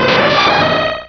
Cri de Cizayox dans Pokémon Rubis et Saphir.